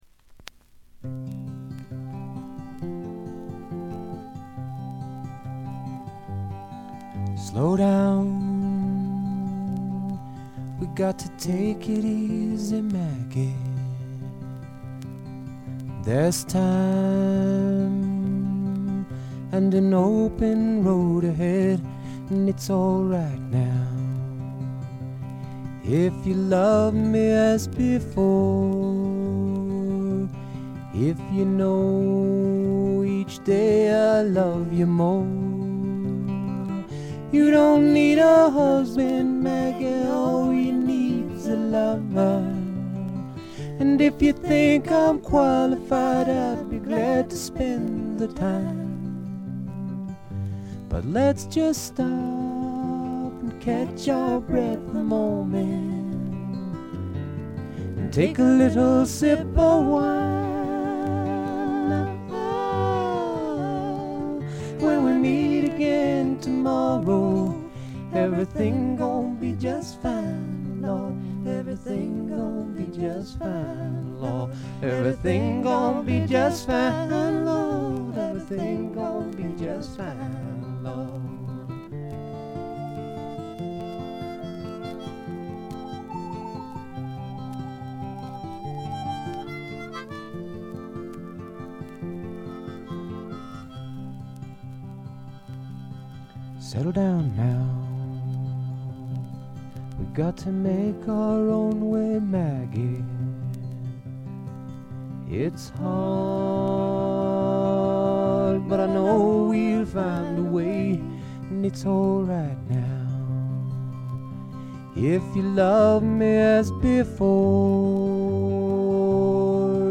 プレスのせいかチリプチ少々出ます。
本人のギター弾き語りを基本に友人たちによるごくシンプルなバックが付くだけのフォーキーな作品です。
とてもおだやかでドリーミーな感覚もがただようフォーク作品です。
試聴曲は現品からの取り込み音源です。
ちなみに試聴曲はA7以外は女性ヴォーカルとのデュエットです。
guitar